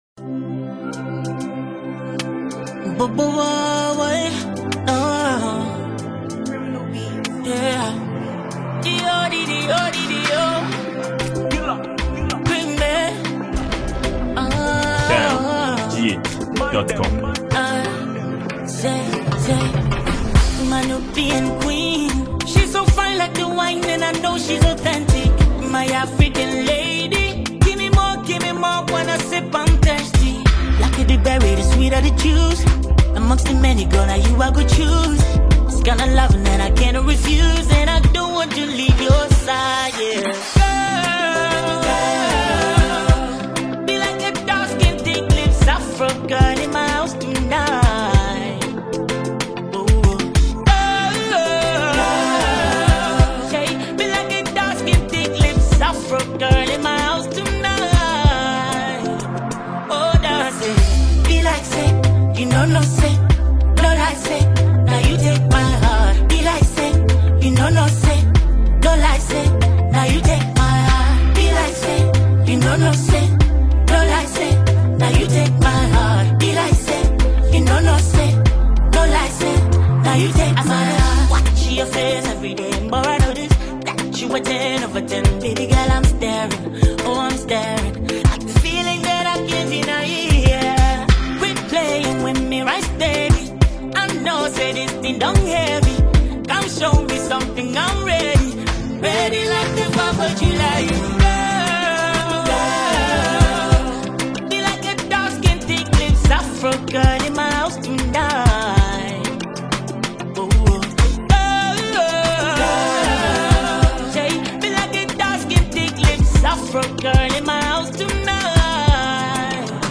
This is an afrobeats song and is available for mp3 download.